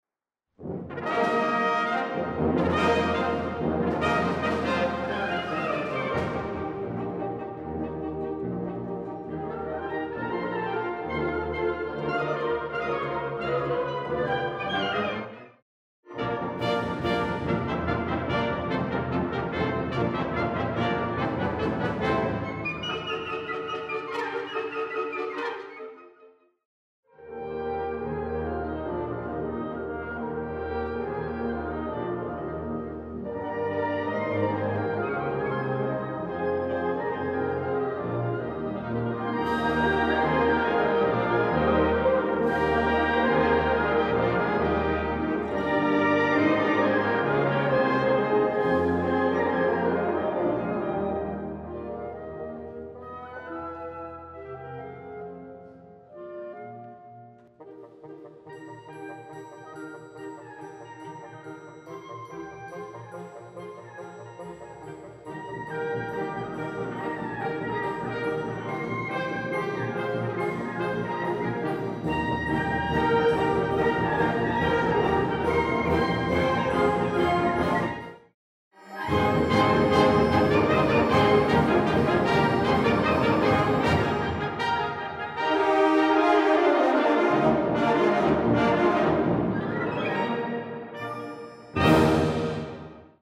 Live Aufnahme
Reformierte Kirche Meilen